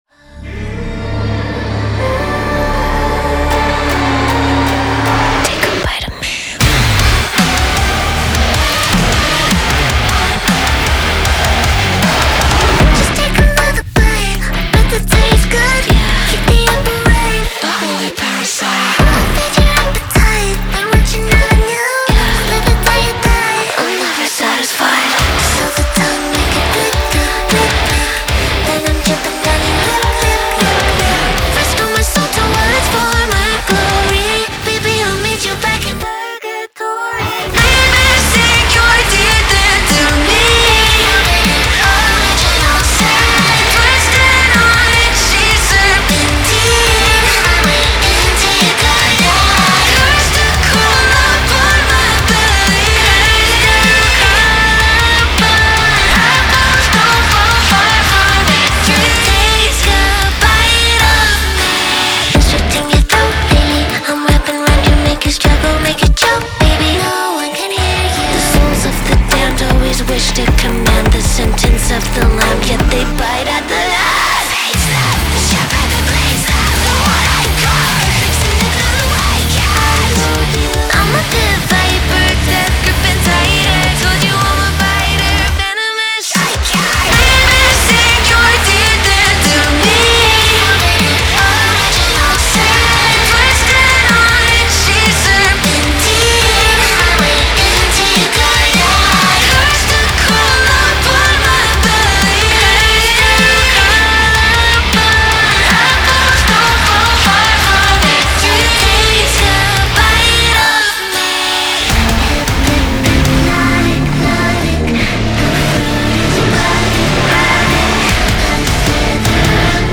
BPM155-155
Audio QualityPerfect (High Quality)
Metal song for StepMania, ITGmania, Project Outfox
Full Length Song (not arcade length cut)